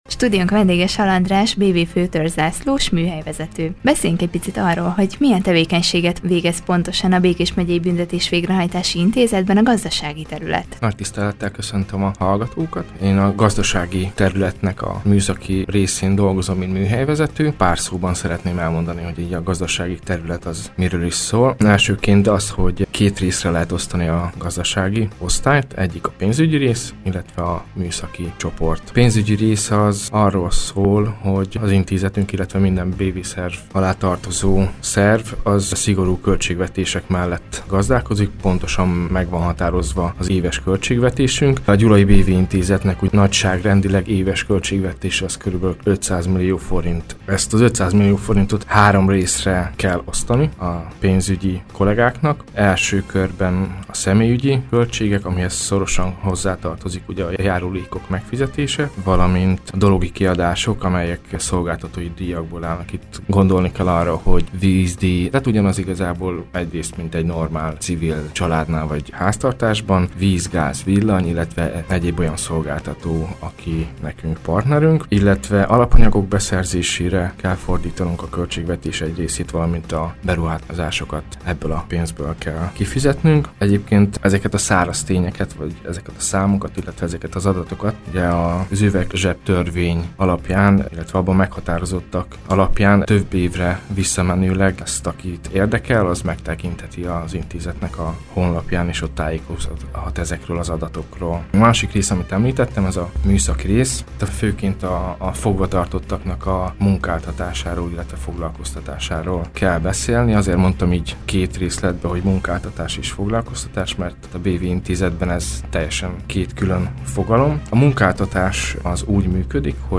a Körös Hírcentrum stúdiójának vendége. Vele beszélgetett tudósítónk a gazdasági terület részeiről, osztályairól valamint a fogvatartottak tanulási lehetőségeiről.